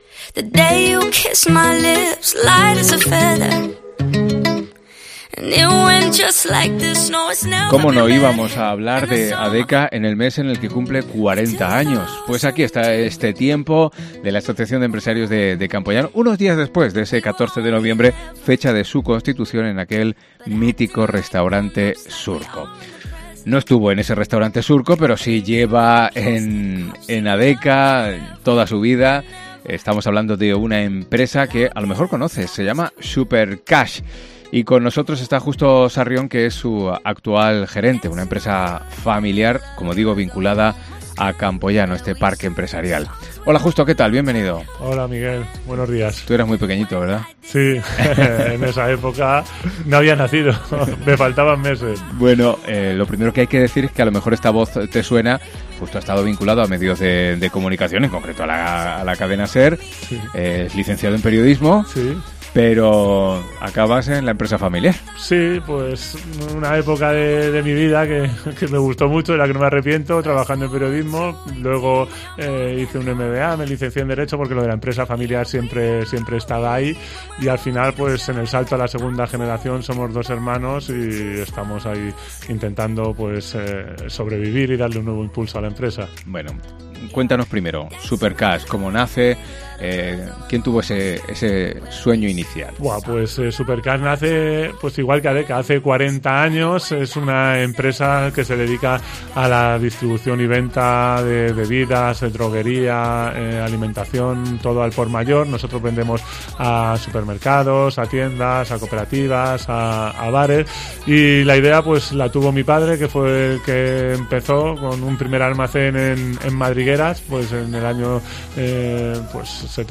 ENTREVISTA COPE